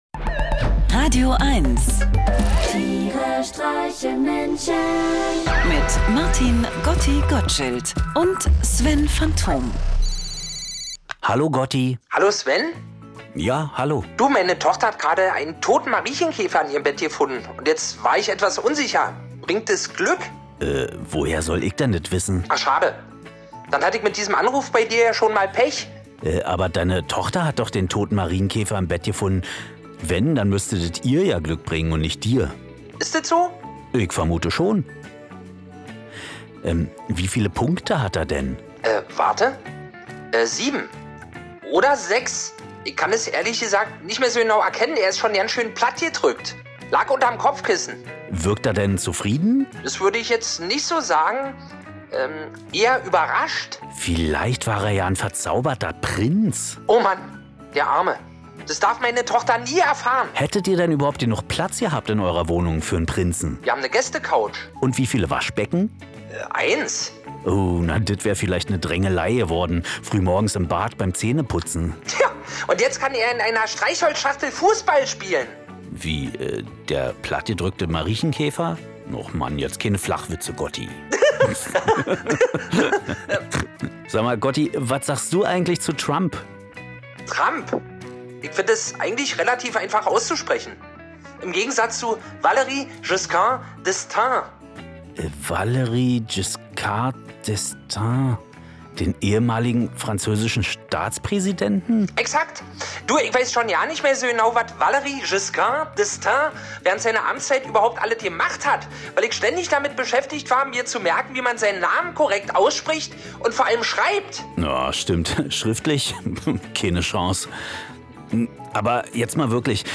Einer liest, einer singt und dabei entstehen absurde, urkomische, aber auch melancholische Momente.